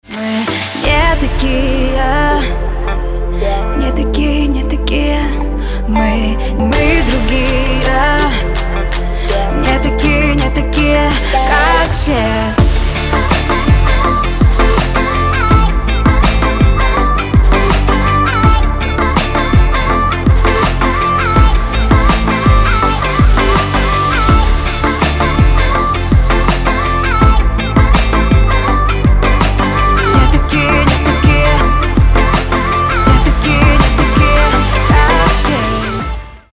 • Качество: 320, Stereo
поп
RnB
красивый женский голос